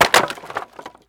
wood_plank_break4.wav